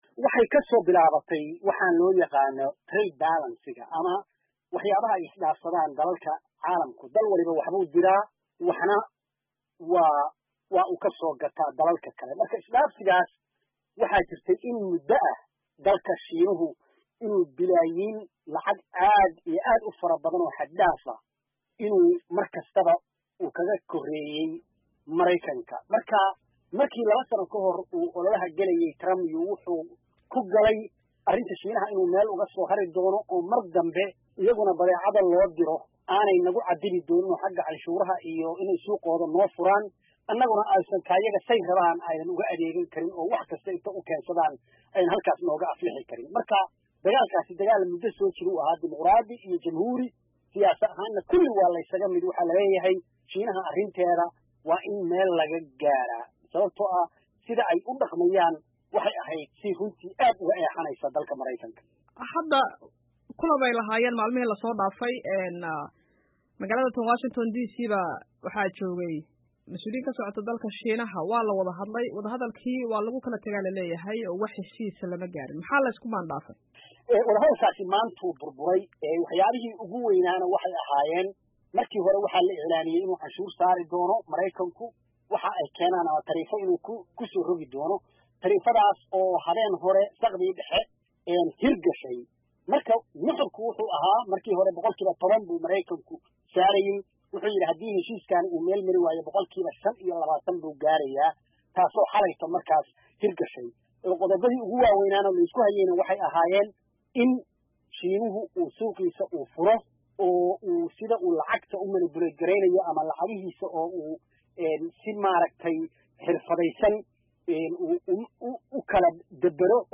ka wareystay